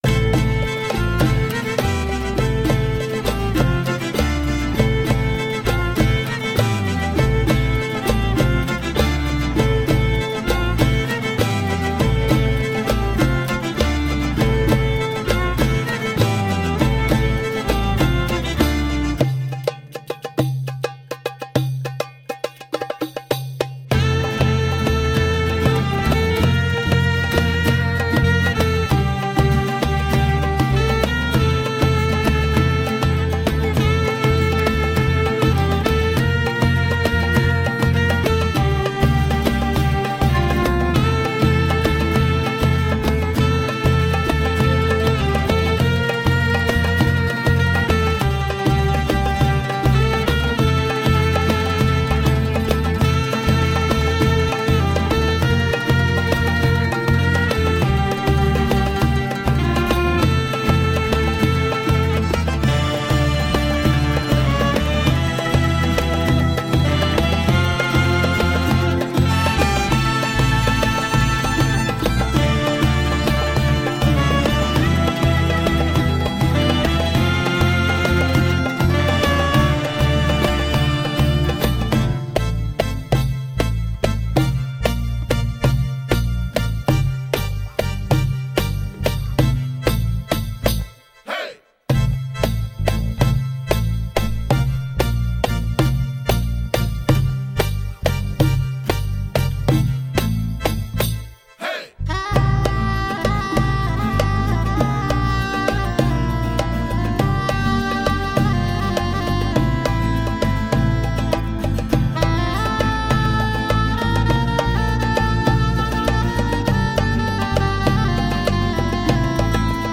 Sultry and percussive mid-east fusion.